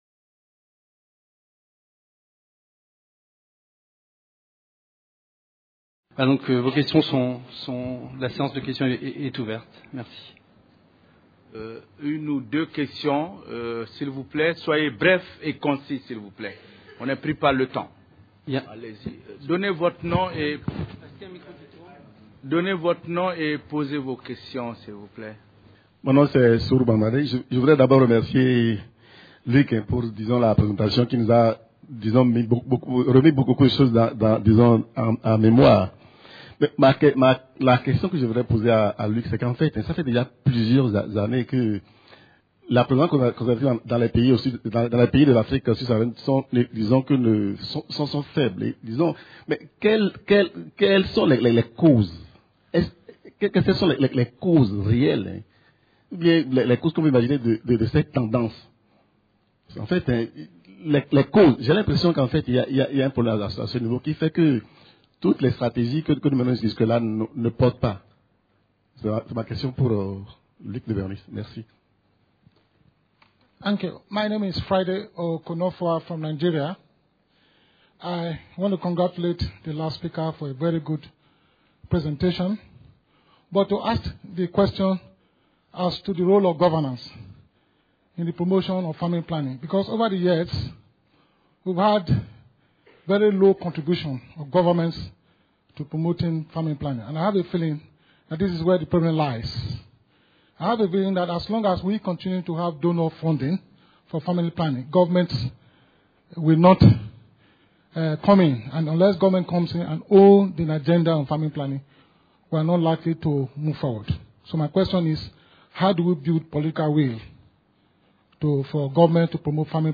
Conférence enregistrée dans le cadre du Colloque International Interdisciplinaire : Droit et Santé en Afrique. Réduction de la mortalité maternelle en Afrique Sub-saharienne, mieux comprendre pour mieux agir. 1ère Session : Fécondité, sexualité et planning familial.